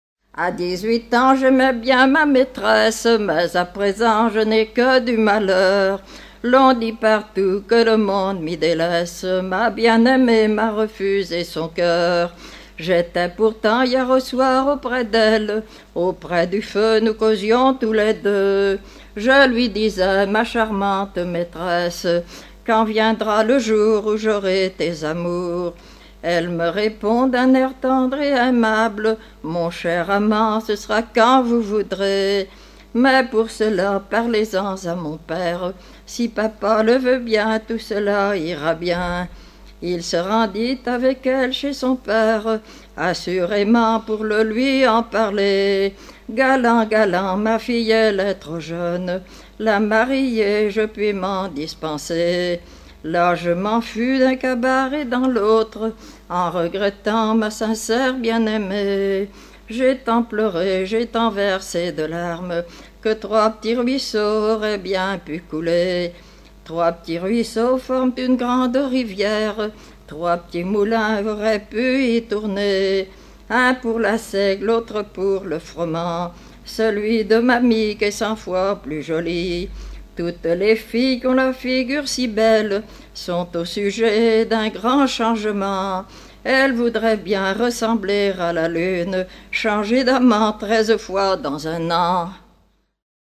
Changer d'amant treize fois dans un an Genre strophique Artiste de l'album collectif Edition discographique Canton du Poiré-sur-Vie, vol. 20 Cote : fra_pdl_ve_poire_sur_vie_avpl28